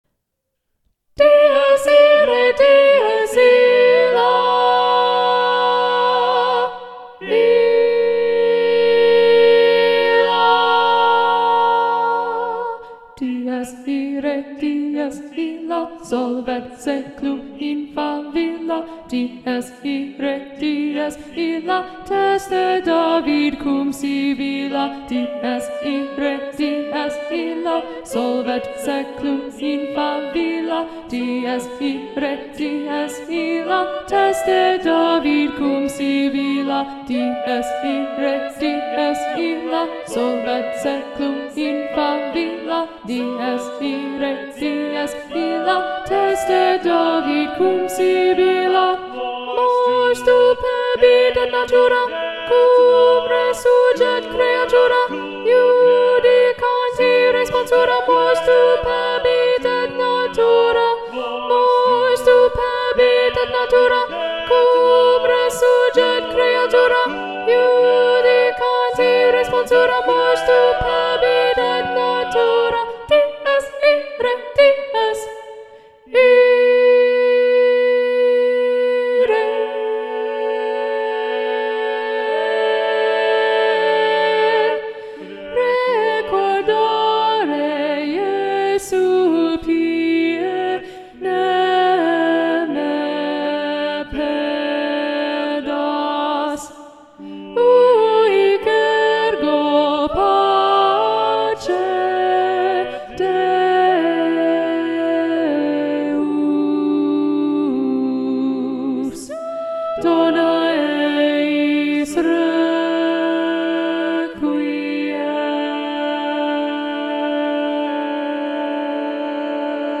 - Œuvre pour chœur à 8 voix mixtes (SSAATTBB)
SATB Alto 1 Predominant